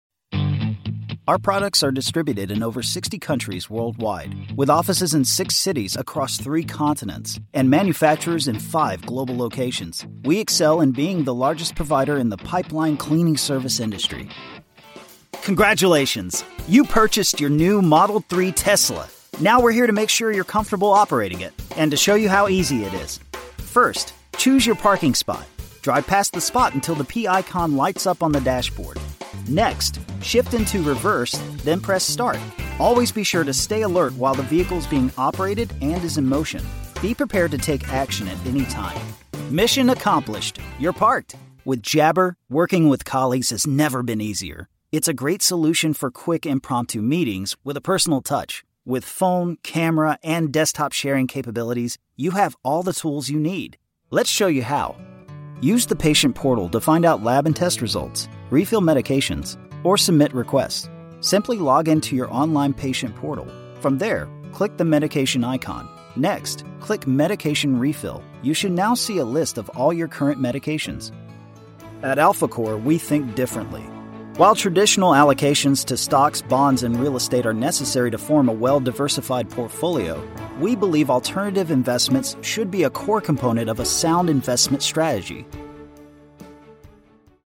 Amostras de Voz Nativa
Demonstração Comercial
Vídeos Explicativos
Microfone Rode NT1, Scarlett Focusrite e Adobe Audition.
Jovem adulto
Meia-idade